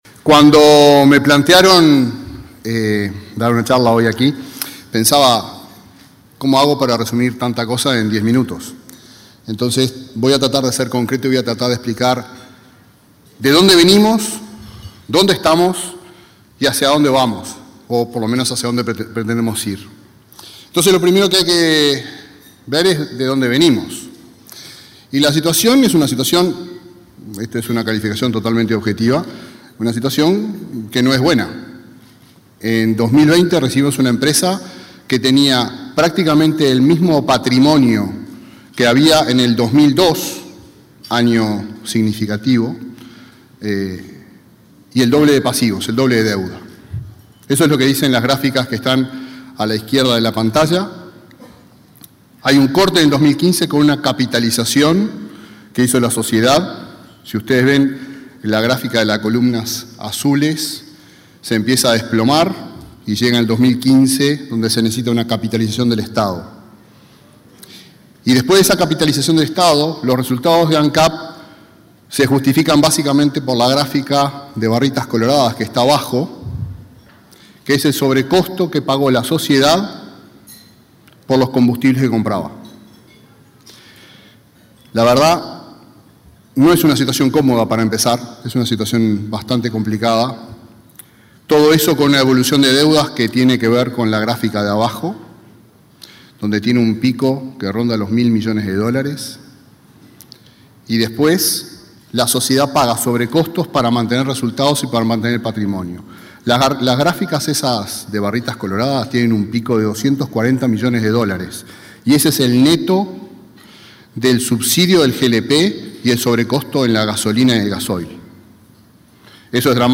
El presidente de Ancap, Alejandro Stipanicic, disertó este miércoles 1.° en un desayuno de trabajo de la Asociación de Dirigentes de Marketing (ADM).